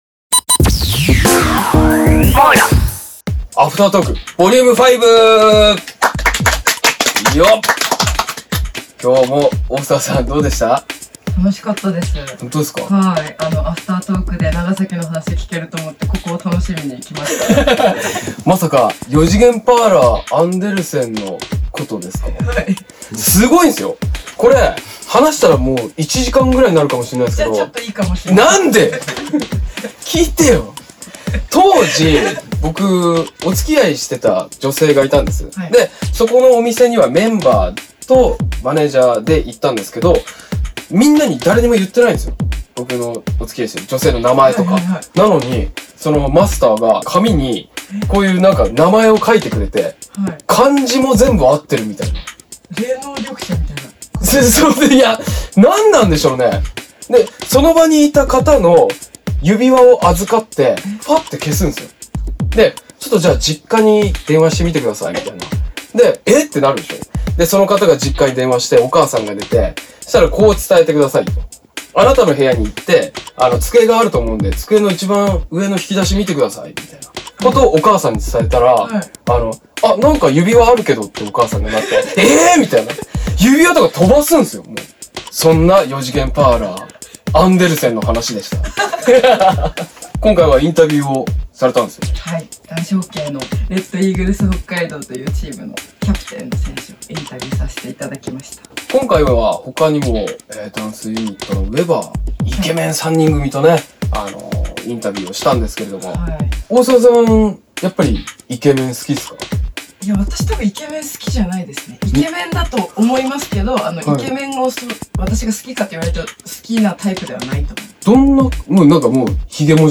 ↓↓↓ アフタートーク Vol.５ ↑↑↑ こちらを押してください！